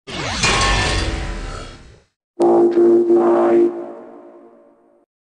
butterfly p e k k a clash royale Meme Sound Effect